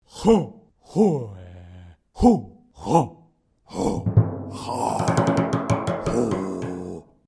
Foreign Film Karate Chant
SFX
yt_3YnqBnHfHNE_foreign_film_karate_chant.mp3